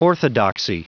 Prononciation du mot orthodoxy en anglais (fichier audio)
Prononciation du mot : orthodoxy